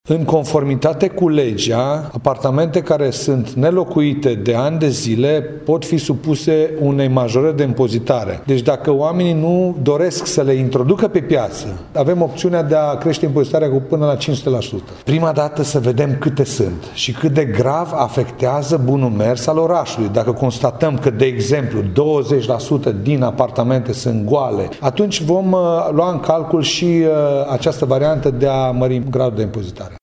Primarul Ioan Popa spune că legea permite acest lucru, dar înainte de a se lua vreo decizie, se va face o analiza a situației, pentru a se cunoaște ce impact are asupra orașului: